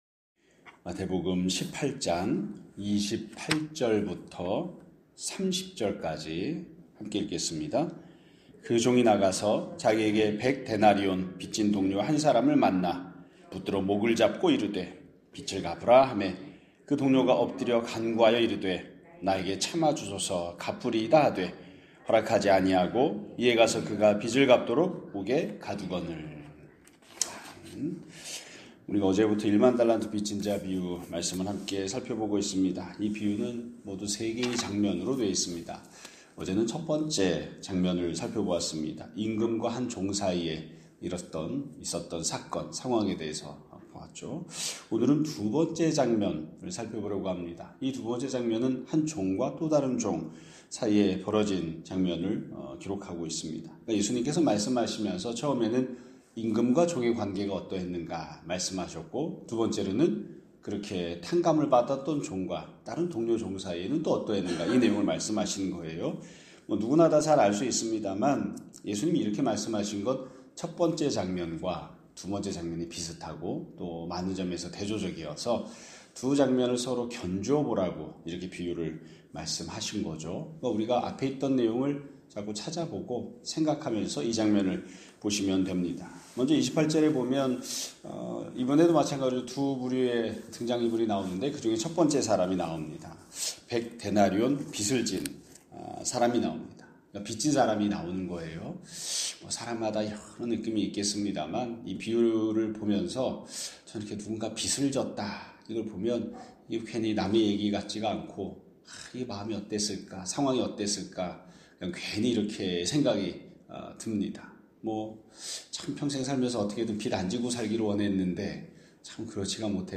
2025년 12월 23일 (화요일) <아침예배> 설교입니다.